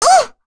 Rehartna-Vox_Damage_05.wav